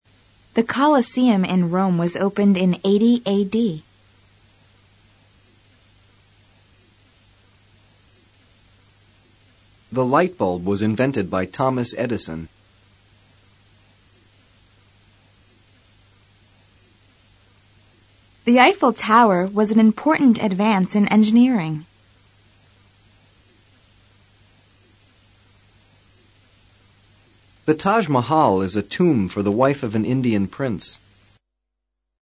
A menudo, los sonidos consonantes finales se ligan con los sonidos vocales que le siguen.